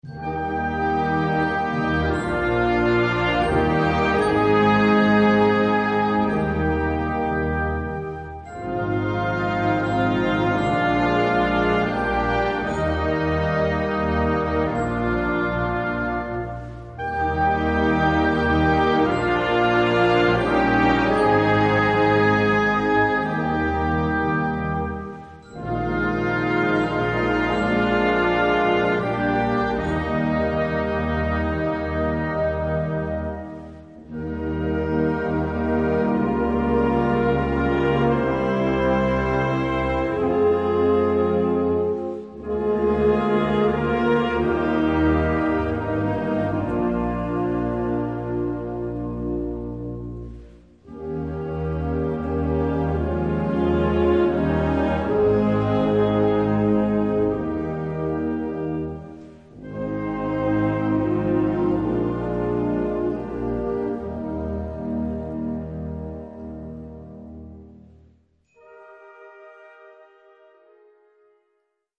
Gattung: Choral
Besetzung: Blasorchester
um das Espressivo-Spiel des Blasorchesters zu üben.